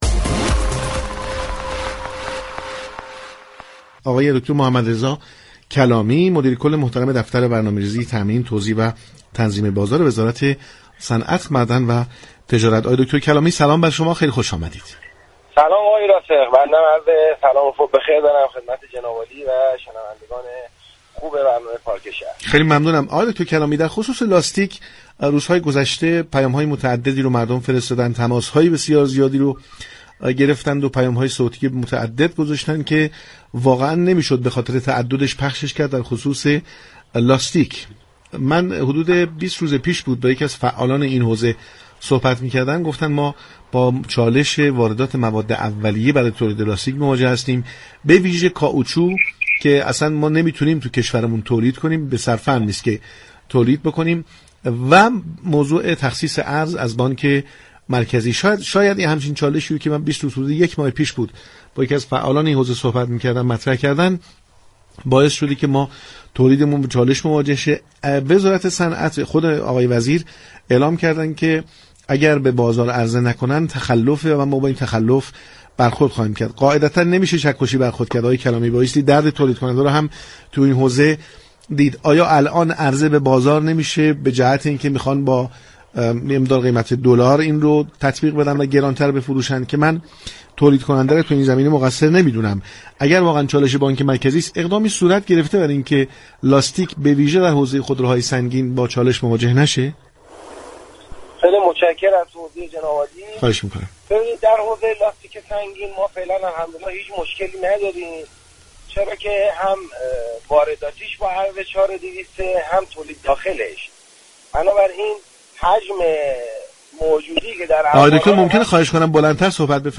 محمدرضا كلامی، مدیركل دفتر برنامه‌ریزی تامین، توزیع و تنظیم بازار وزارت صنعت، معدن و تجارت در گفتگو با پارك شهر درباره بازار لاستیك در كشور توضیح داد.